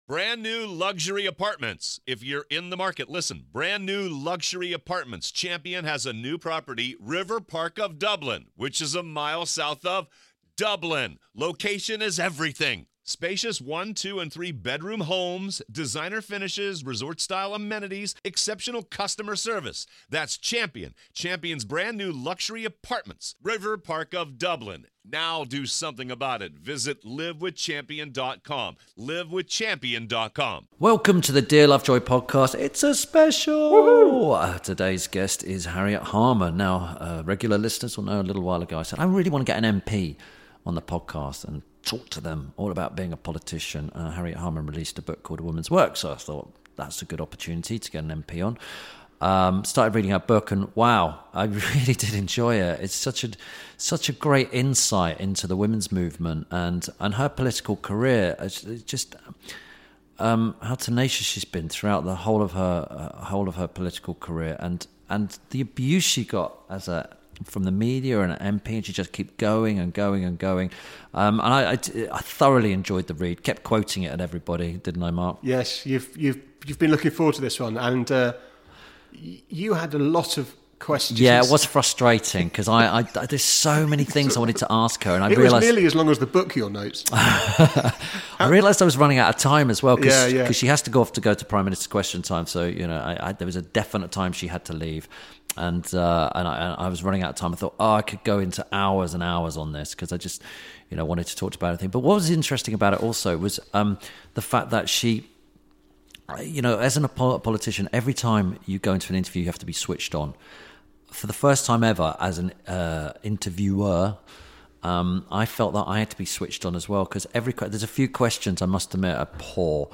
This week Tim Lovejoy talks to Member of Parliament Harriet Harman. Whilst chatting about Harriet’s recent book, they discuss years of battling for equality, power in party politics and potato ricers.